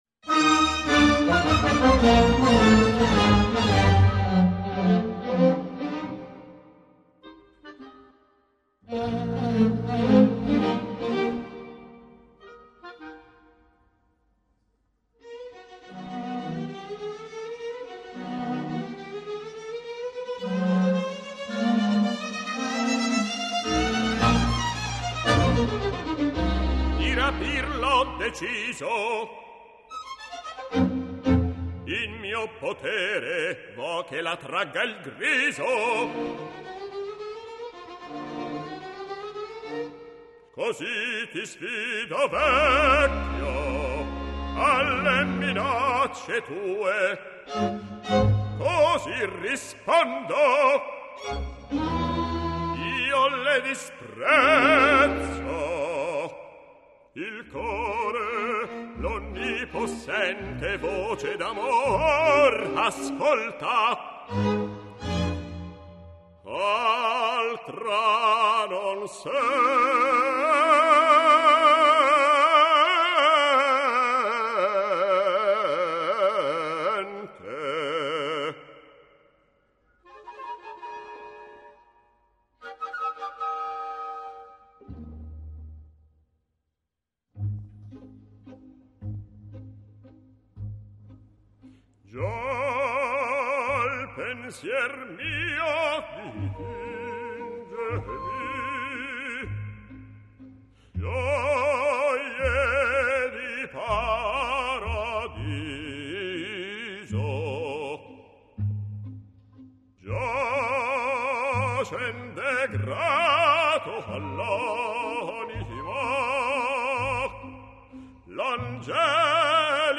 Don Rodrigo [Bariton]